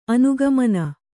♪ anugamana